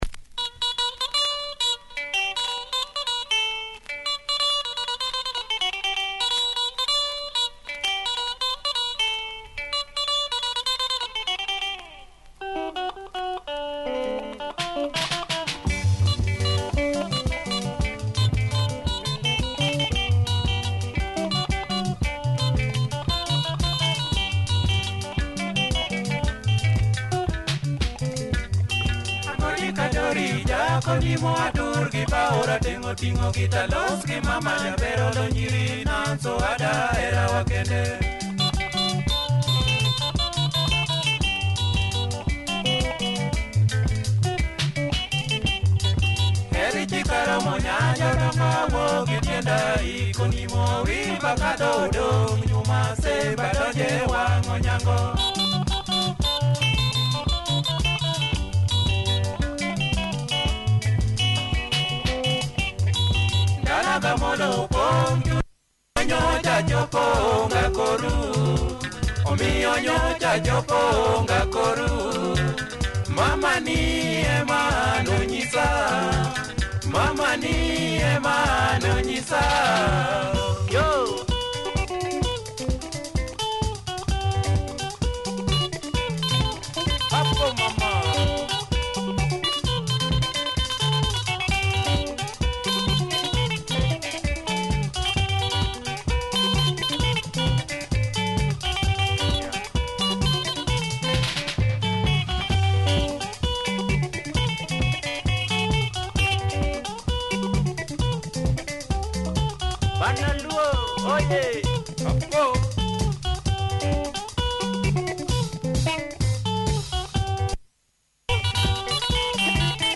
Nice luo benga, clean copy! https